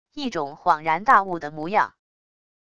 一种恍然大悟的模样wav音频生成系统WAV Audio Player